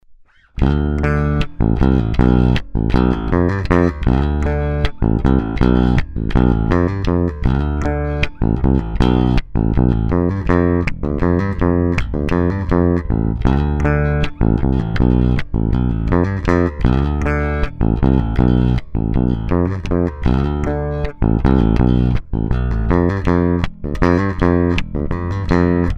Tonalités à fond
Le positif : un son si particulier, pincé et rempli de medium qui perce le mix.
ToneAigu_Grave.mp3